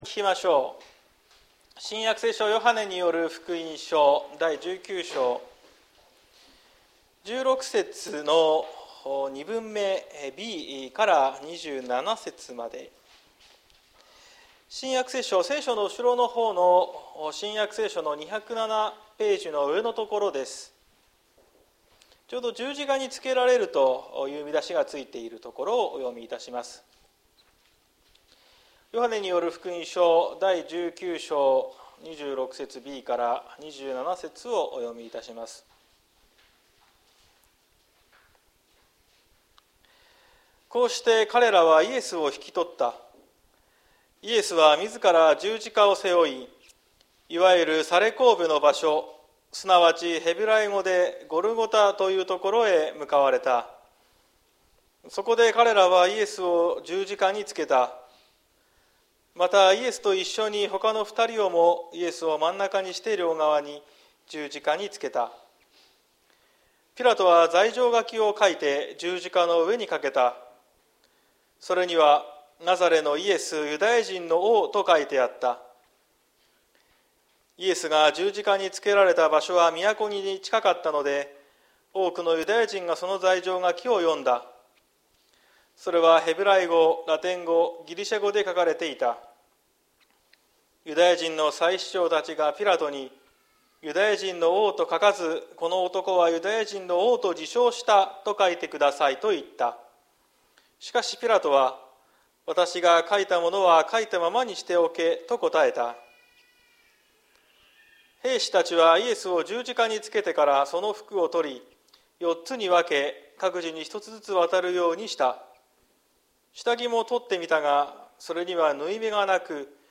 2023年07月23日朝の礼拝「神の家族」綱島教会
説教アーカイブ。